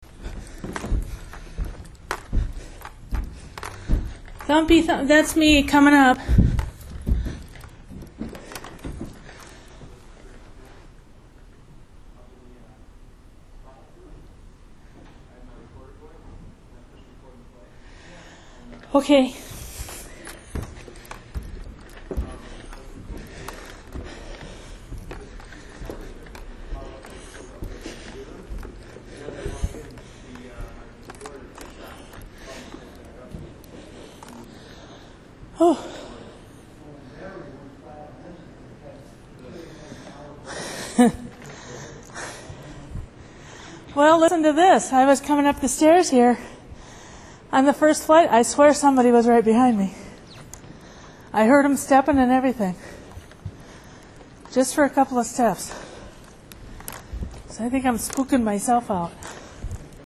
Audio recordings revealed some possible EVPs (electronic voice phenomena) and unusual noises which are included on a CDROM.
As she is walking, what seems to be a second set of footsteps is heard for a few seconds.